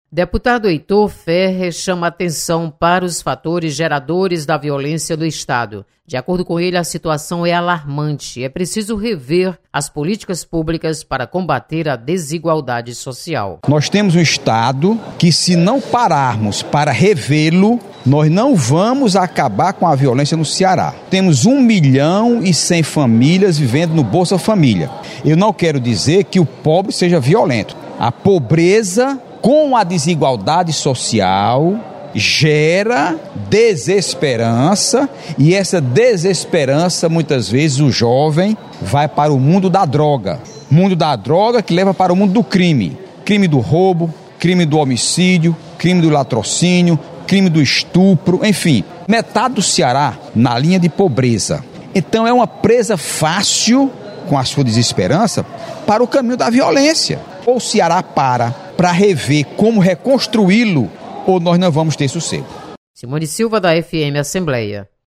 Deputado Heitor Férrer mostra preocupação com os motivadores da violência no Ceará. Repórter